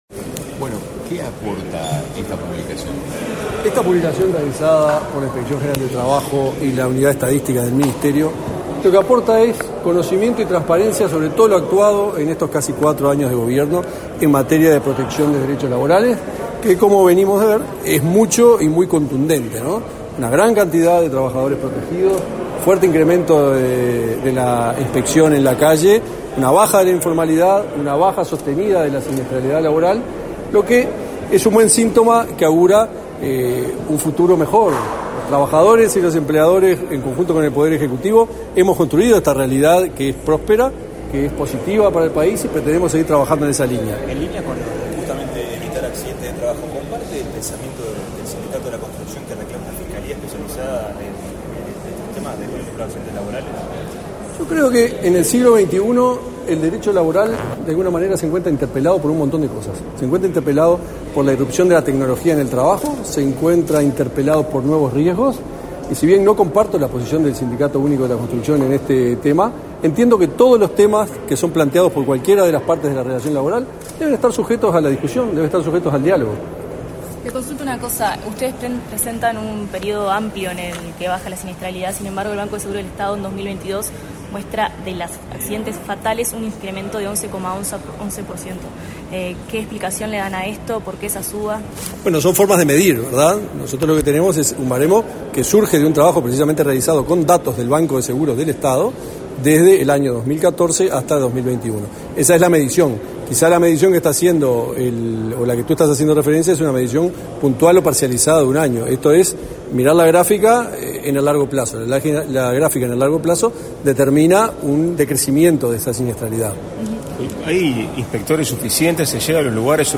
Declaraciones a la prensa del inspector general de Trabajo y la Seguridad Social, Tomás Teijeiro.
El presidente de la República, Luis Lacalle Pou, participó, este 27 de noviembre, en la presentación de datos de protección laboral de los trabajadores por parte del Ministerio de Trabajo y Seguridad Social (MTSS). Tras el evento, el inspector general de Trabajo y la Seguridad Social, Tomás Teijeiro, realizó declaraciones a la prensa.